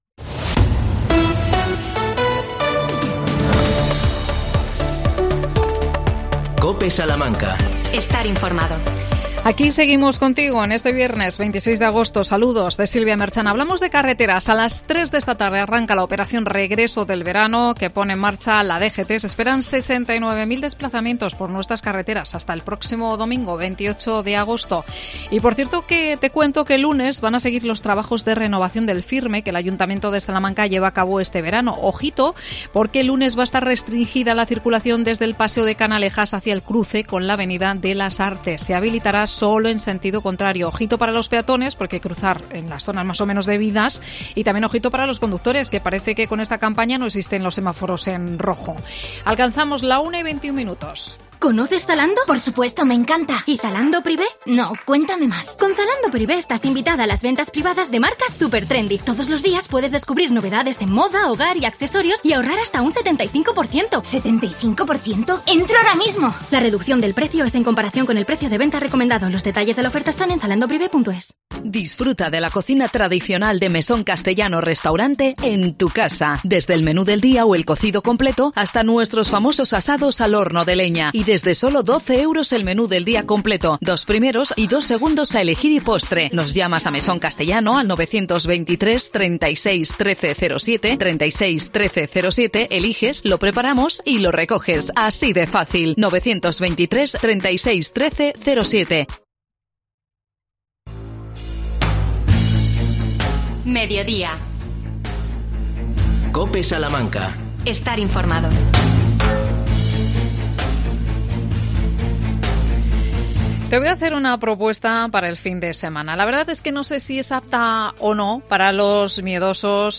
AUDIO: Llega a Carbajosa la Survival Zombie mañana sábado 27 de agosto. Nos cuenta todos los detalles Alba Hernández, concejala de Juventud.